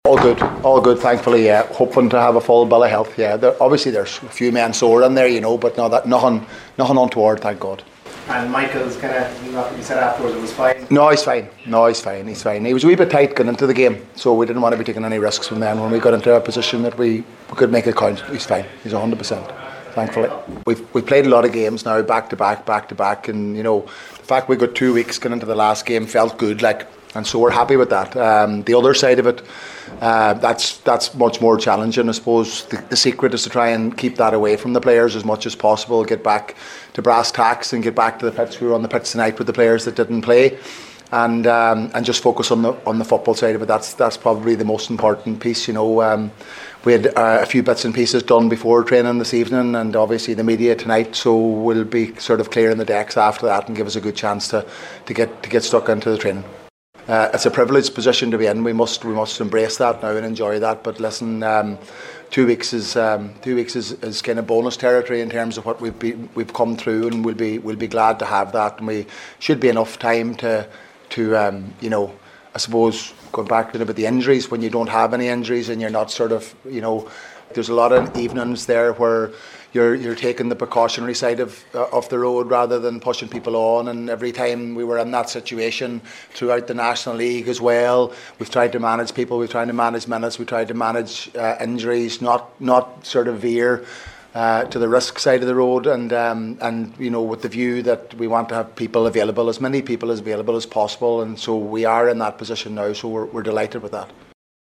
McGuinness was speaking last night as Donegal held their pre-final press night to provide an update.